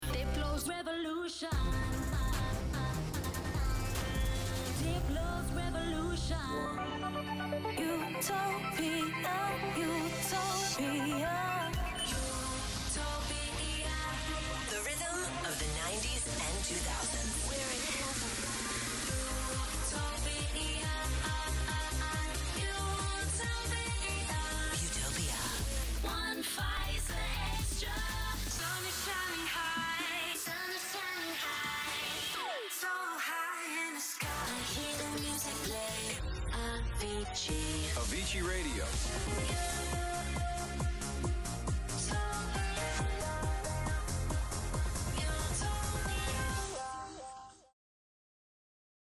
Female
British English (Native)
2025 Imaging Singing Reel Shorts .mp3
Microphone: Rode NT2-A, Shure SM7B, Shure SM58